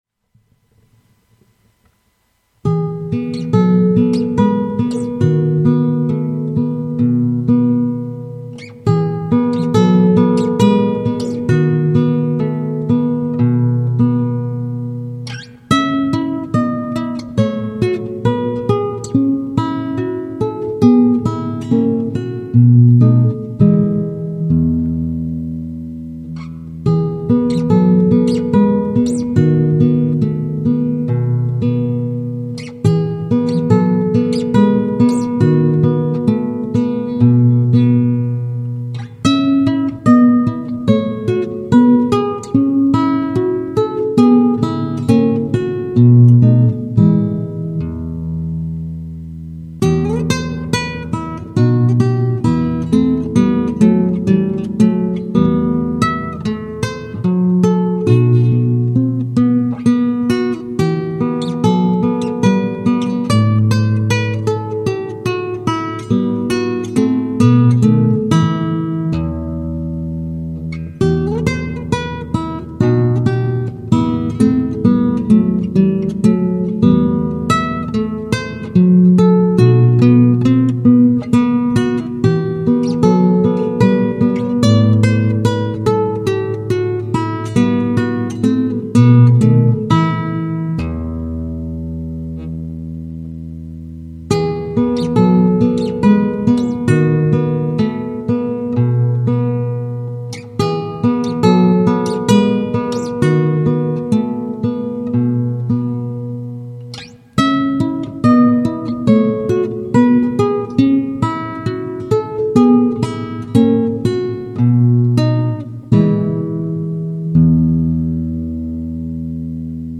Classical Guitarist in the West Midlands
guitar
• Solo